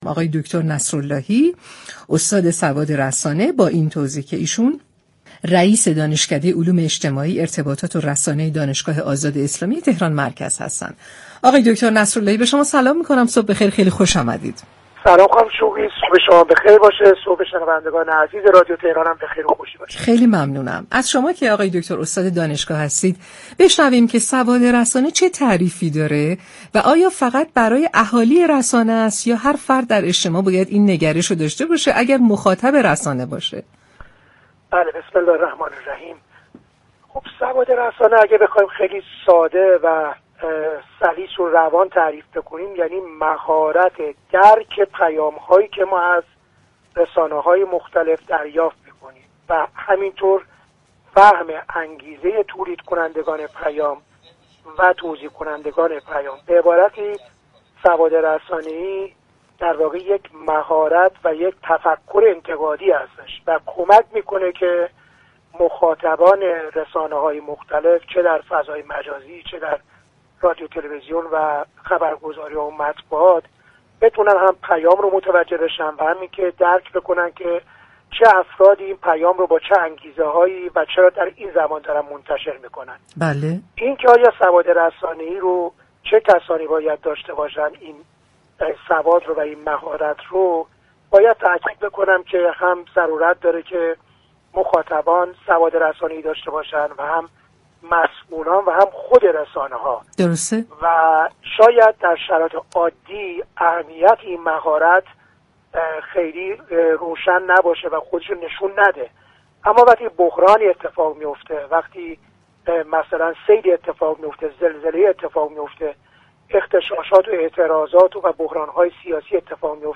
در گفت و گو با شهر آفتاب رادیو تهران